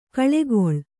♪ kaḷegoḷ